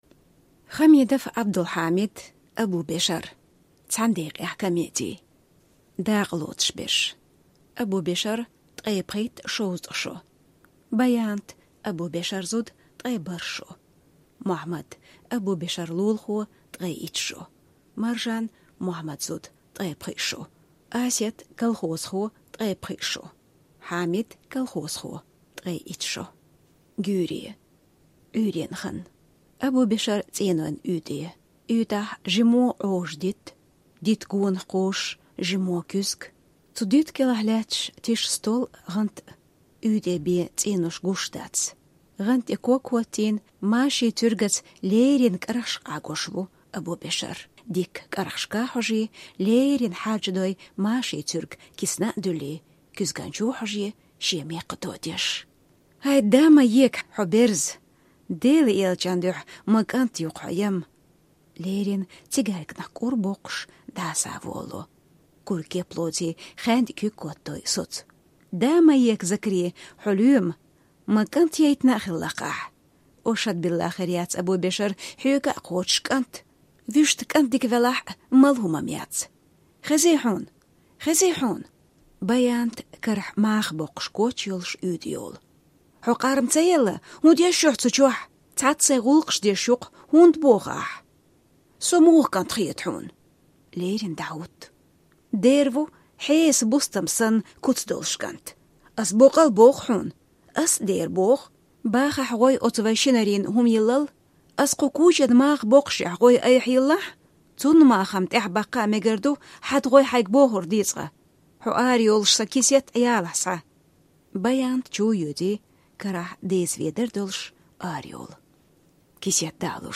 Хамидов Iабдул-Хьамид. Абубешар. (комеди)